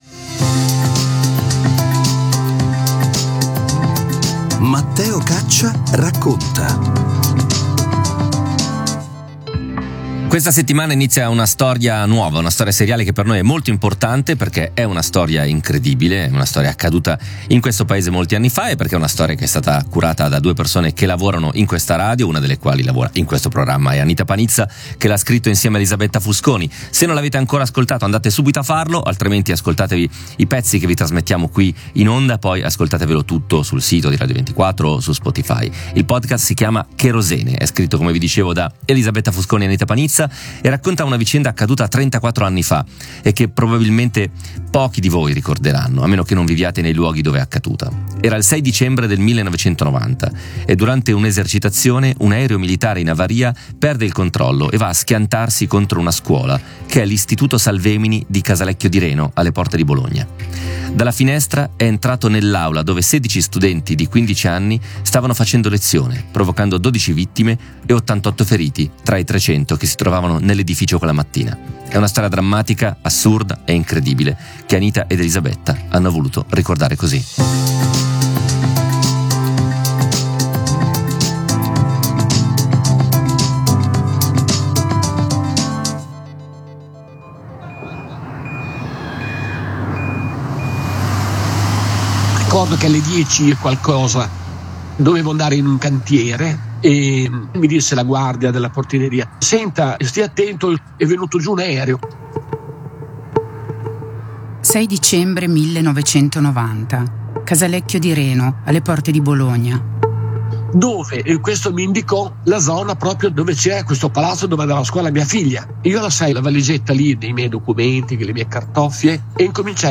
Cherosene. Storia di un aereo contro una scuola ricostruisce questa vicenda attraverso le testimonianze dei sopravvissuti, di chi è corso in aiuto nell’immediato e di coloro che da più di 30 anni si battono perché questa tragedia non sia dimenticata.